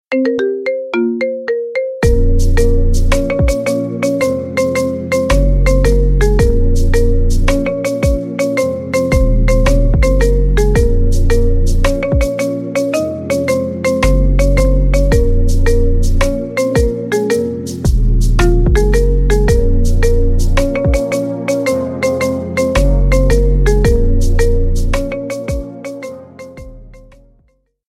Рингтоны Без Слов » # Рингтоны Ремиксы